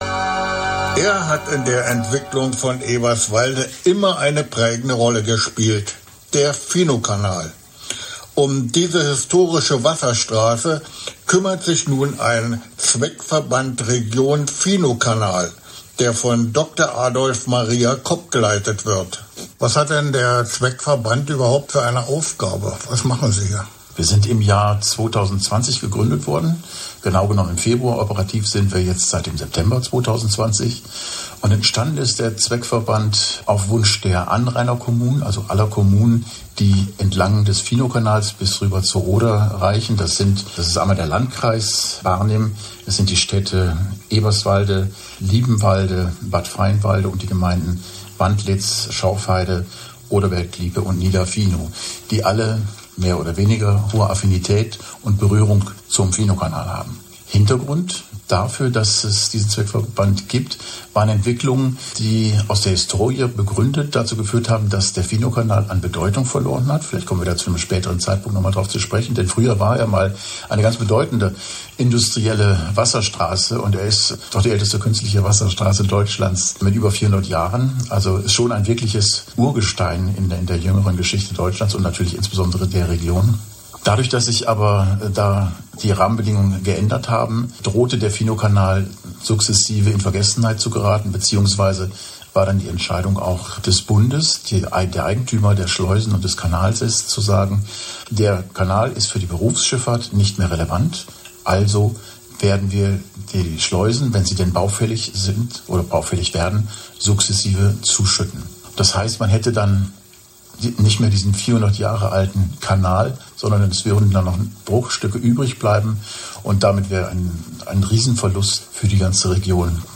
Aktuelle Informationen zu den Baumaßnahmen Aktuelle Einschränkungen Bautagebuch Schleusensanierung Häufige Fragen Radio Ginseng - Interview zur Schleusensanierung Im Dezember 2024 konnte man auf Radio Ginseng unter der Rubrik Brandenburger Orte verschiedene Interviews zu Attraktionen in Eberswalde hören.
Interview_Radio_Ginseng_Finowkanal.mp3